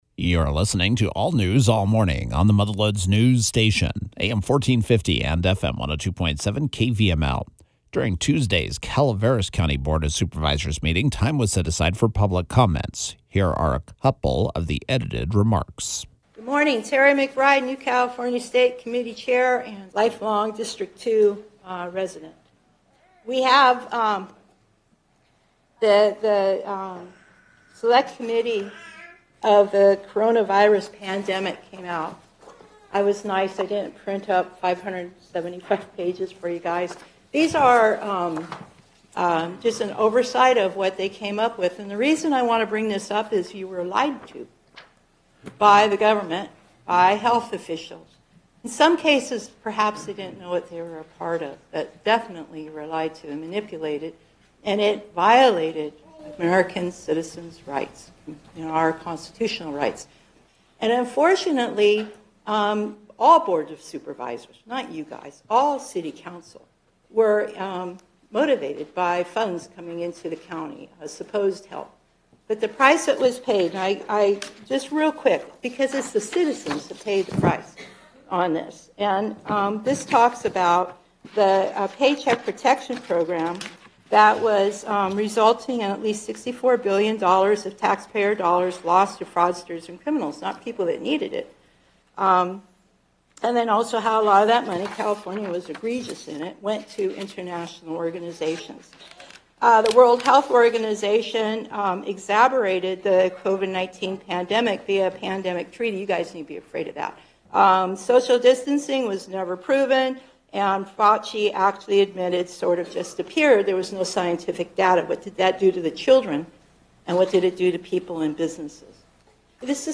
During Tuesday’s Calaveras Supervisors Meeting time was set aside for public comments.
Thursday’s KVML “Newsmaker of the Day” included two of the edited remarks: